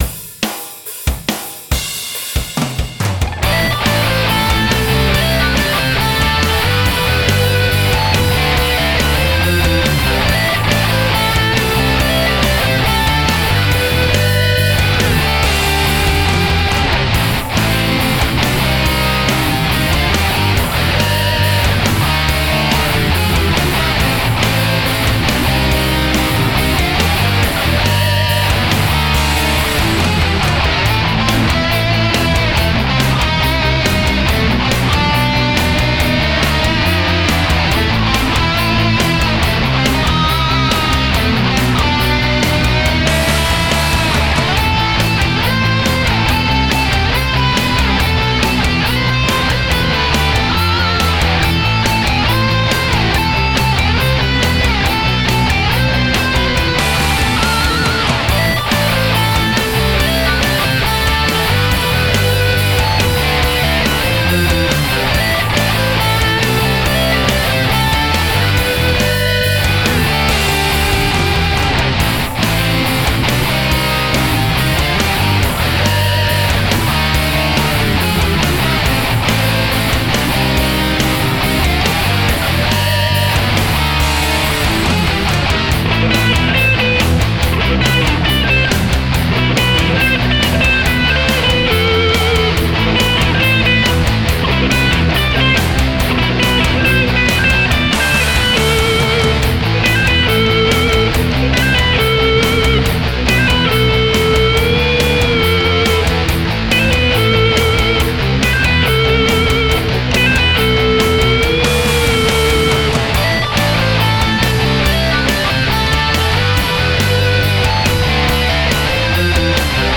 R&B/ Hip-Hop